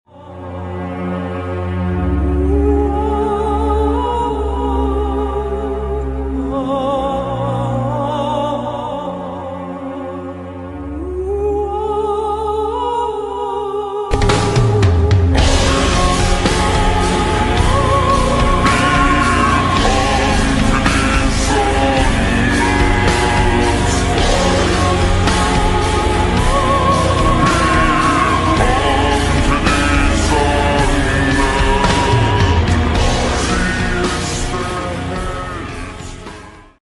Weed Zapper Annihilator 12R30 Electric Sound Effects Free Download
Weed Zapper Annihilator 12R30 electric sound effects free download